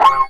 41 GIT03  -R.wav